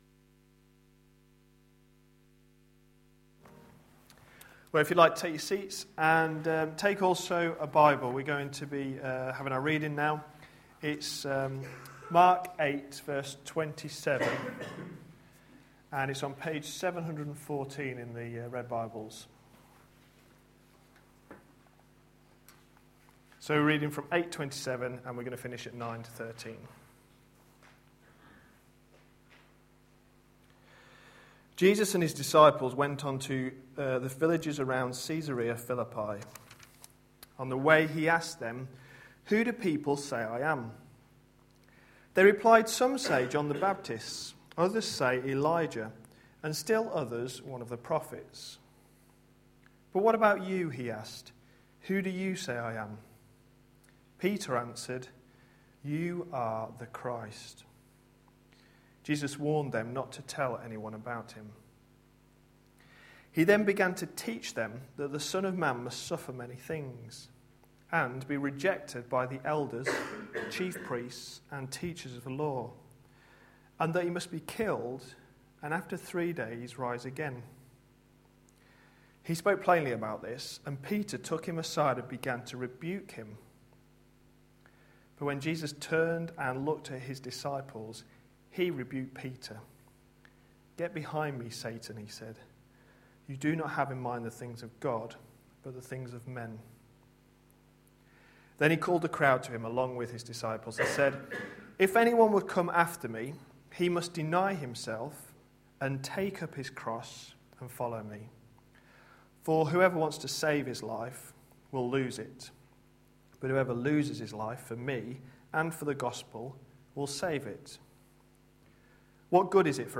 A sermon preached on 5th February, 2012, as part of our Mark series.